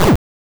PlayerSFX
bfxr_missileexplode.wav